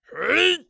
ZS蓄力5.wav
ZS蓄力5.wav 0:00.00 0:00.70 ZS蓄力5.wav WAV · 61 KB · 單聲道 (1ch) 下载文件 本站所有音效均采用 CC0 授权 ，可免费用于商业与个人项目，无需署名。
人声采集素材/男3战士型/ZS蓄力5.wav